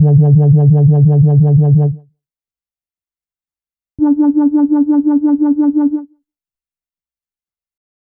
Session 14 - Bass Wobble.wav